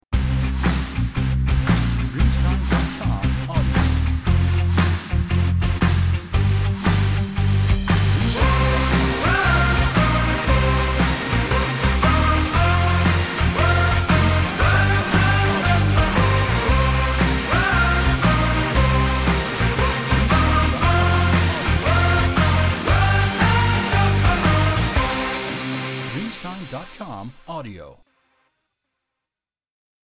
Energetic Indie Rock [30s]